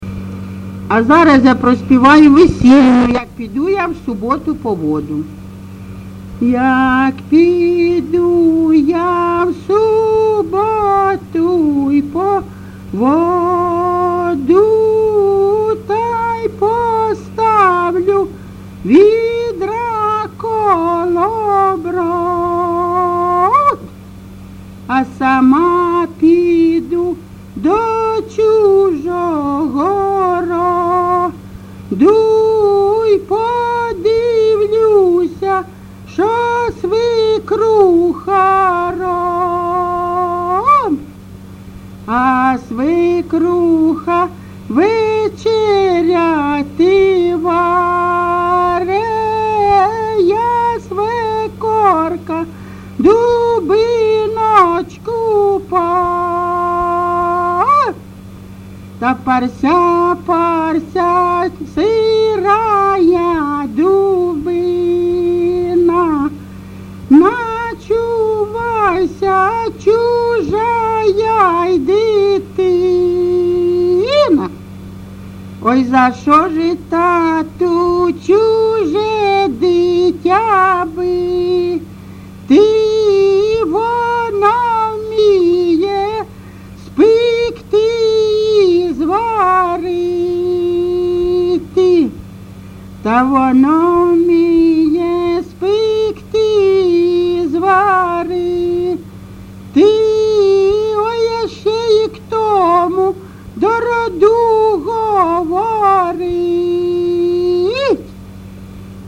ЖанрВесільні
Місце записус. Калинове Костянтинівський (Краматорський) район, Донецька обл., Україна, Слобожанщина